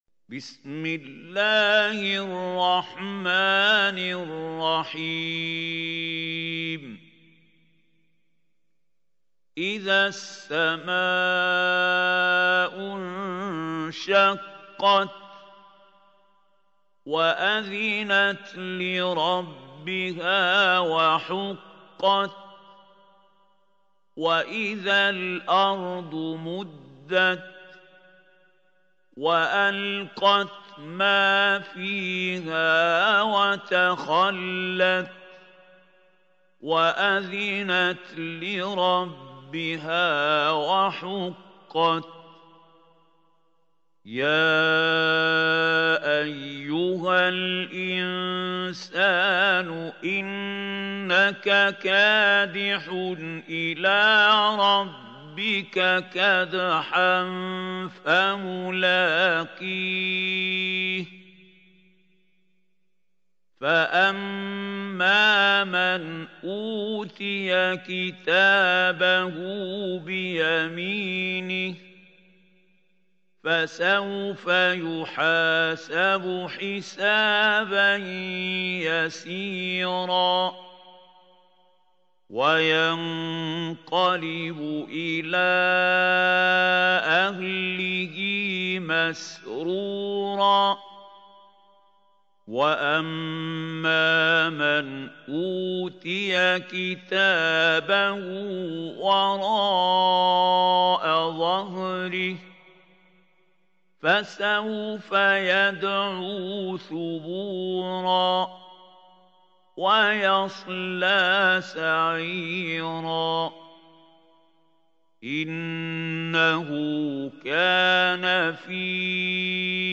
سورة الانشقاق | القارئ محمود خليل الحصري